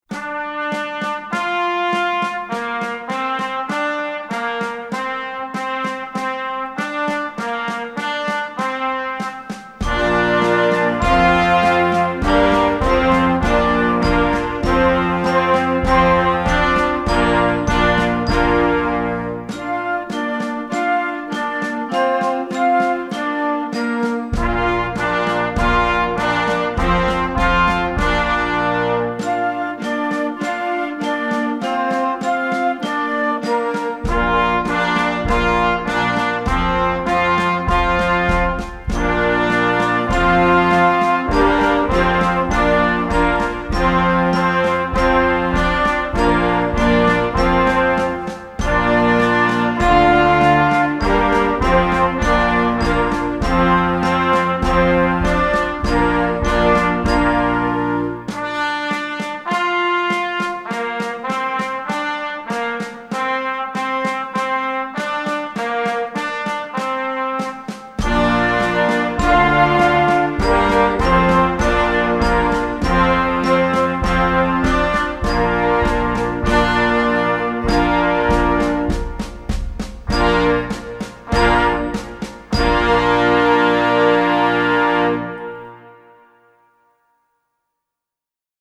Advanced Band